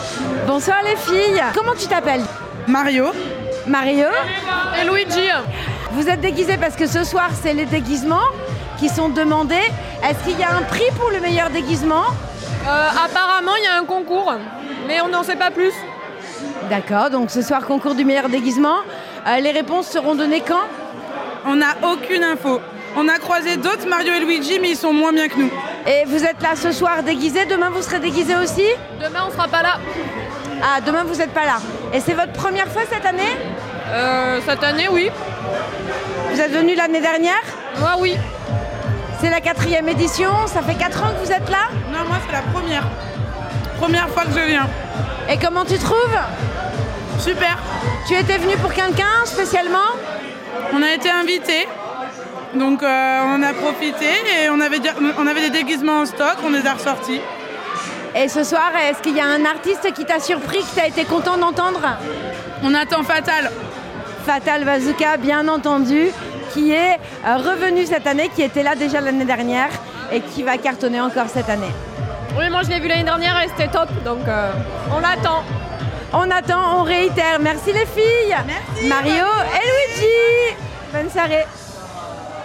« Mario » & « Luigi » couple célèbre de nos consoles de jeux était en loges ce premier jour du Festi’Malemort, premier jour qui était aussi celui du concours du meilleur déguisement.
Interviews Festi'malemort email Rate it 1 2 3 4 5